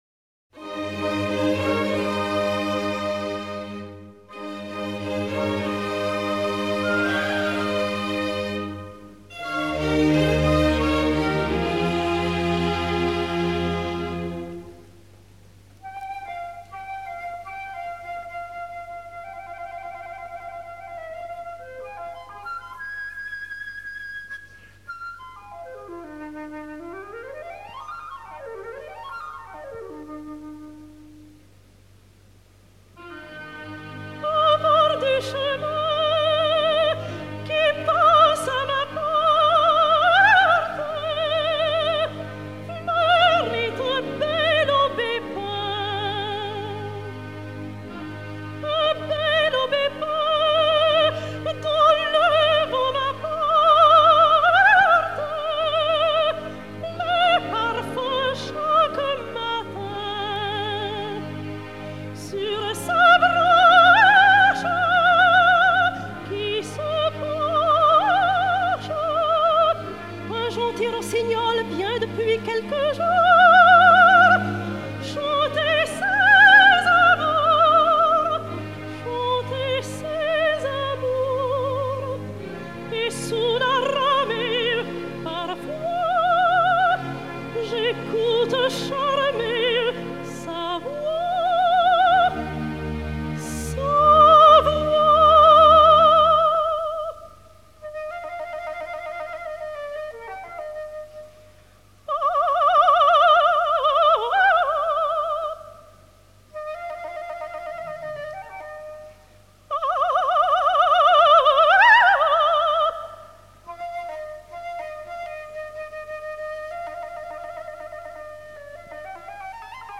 enr. au Théâtre des Champs-Elysées en mars 1948
enr. à la Schola Cantorum à Paris en 1955